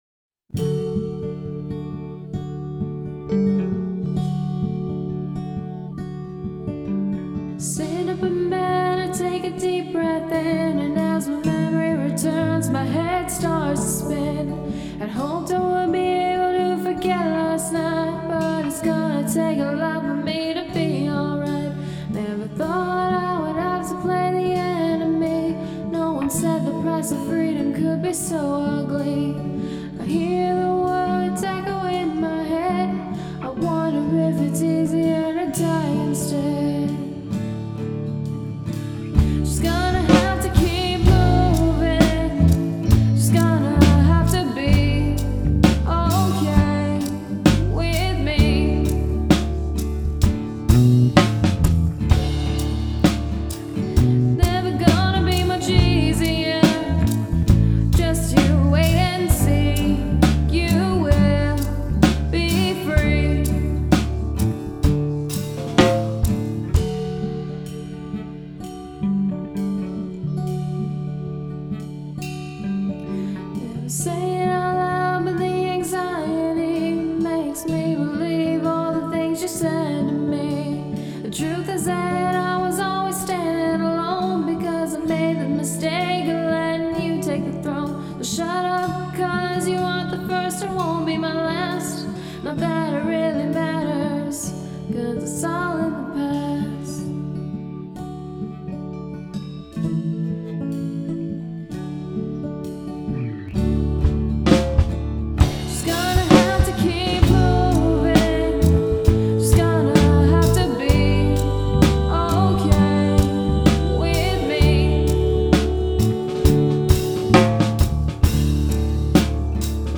Musically I took inspiration from the strumming pattern of Pink Floyd’s “Brain Damage” but used a more relaxed sound.